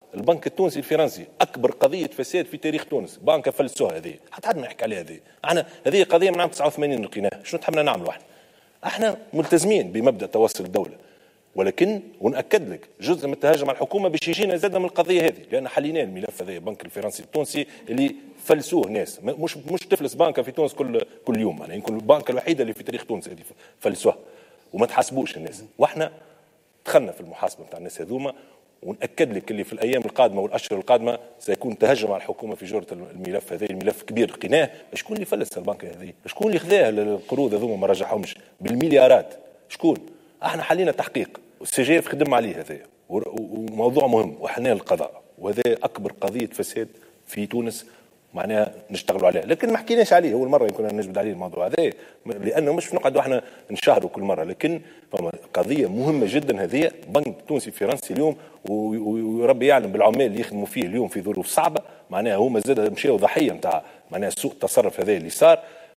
أكد رئيس الحكومة يوسف الشاهد في حوار بثته القناة الوطنية الأولى مساء اليوم الأحد أن حكومته فتحت أكبر ملف فساد شهدته تونس عبر تاريخها والمتعلق بالبنك التونسي الفرنسي.